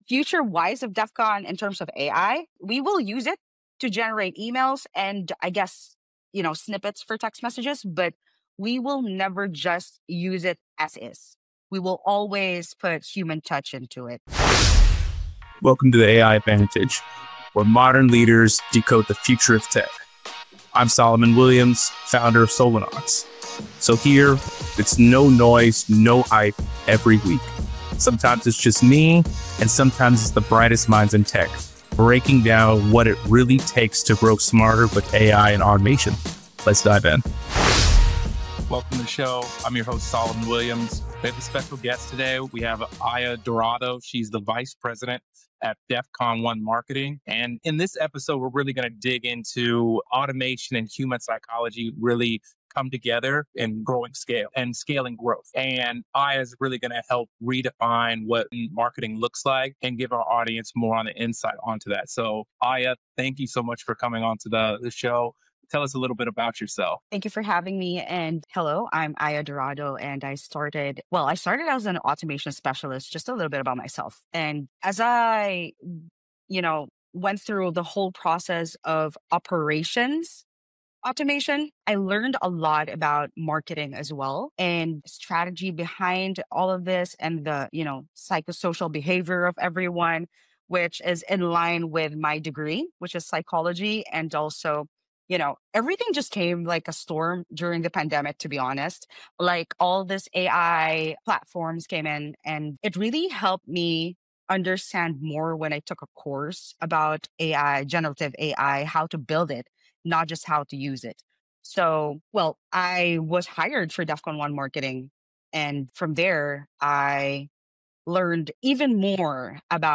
Real conversations with people building the future of IT, SaaS, and cloud industries. Perfect for anyone navigating AI adoption, implementing automation, or leveraging technology to grow their business or career.